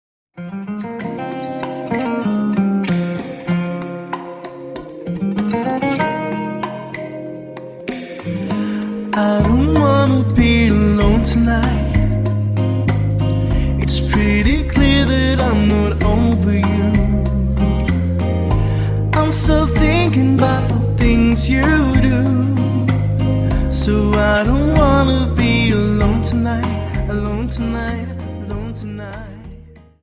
Dance: Rumba 24